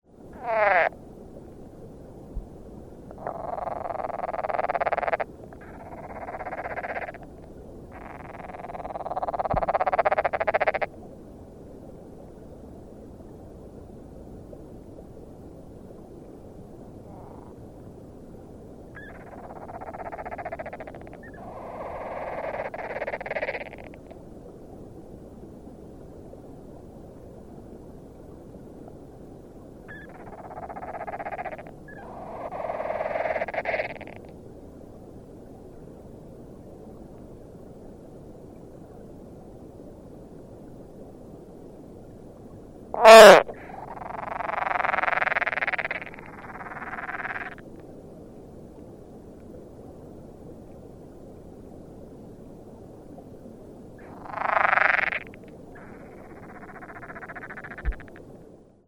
Underwater Calls
Air temperature was 78 degrees F, water temperature 65 degrees F. Several male frogs were gathered in a small pool near some recently-deposited egg masses. Other frogs were situated in nearby pools and their calls could be heard faintly.
Sounds  This is a 59 second recording of underwater sounds made by a group of frogs.